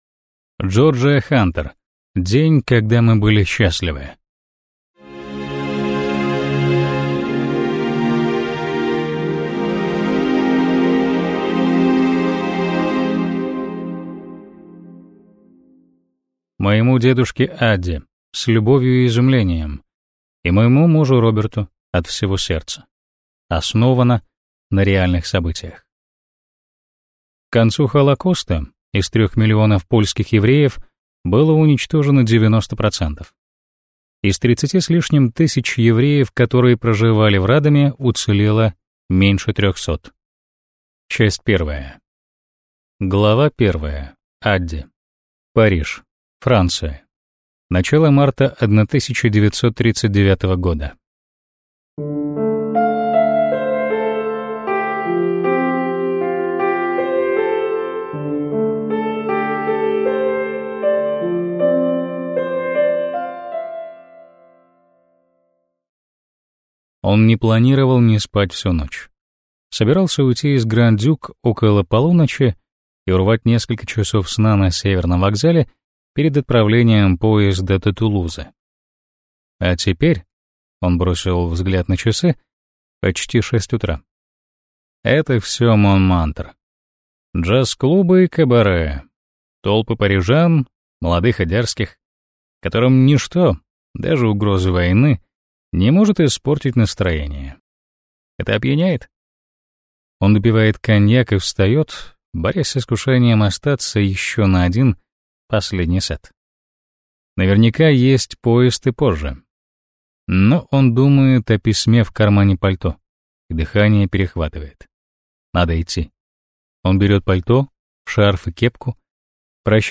Аудиокнига День, когда мы были счастливы | Библиотека аудиокниг